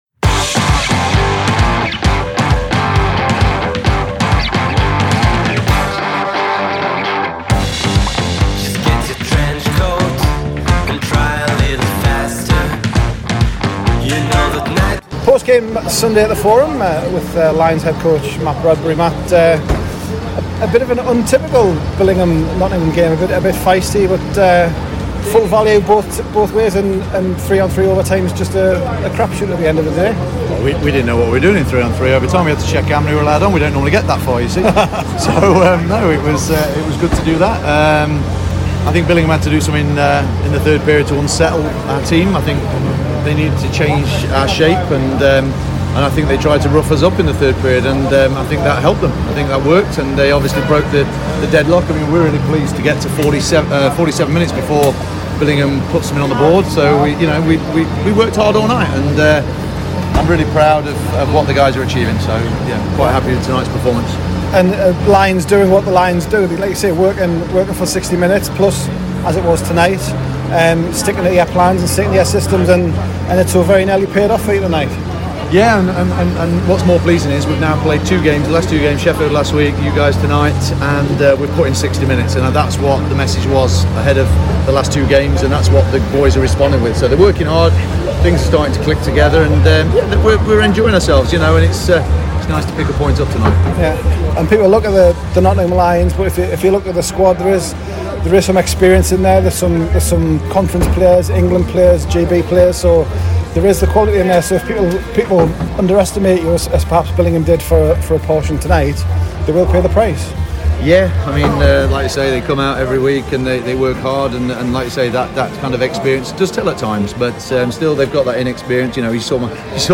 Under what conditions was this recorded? in post-game discussion on Sunday night after Billingham's 2-1 OT win at the Forum.